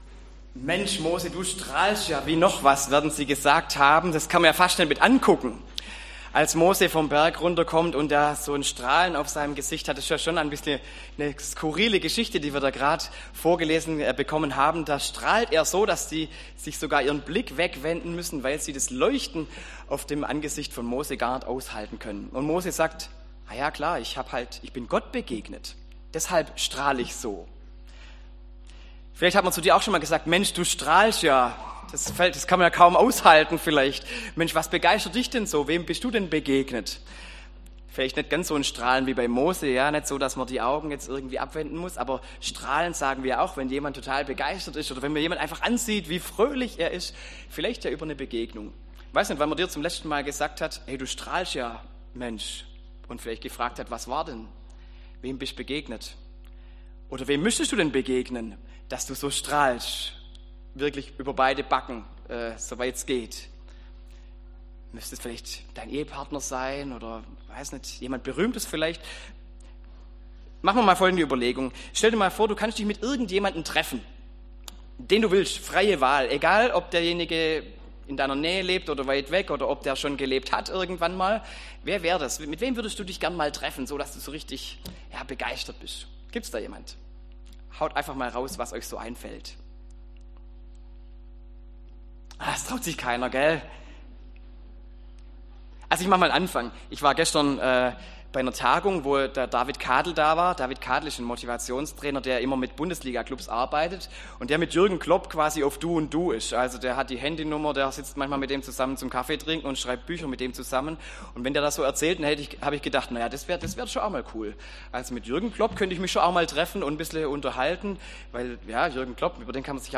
Predigt im Gottesdienst am letzten Sonntag nach Epiphanias